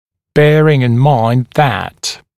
[‘beərɪŋ ɪn maɪnd ðæt][‘бэарин ин майнд зэт]помня о том, что…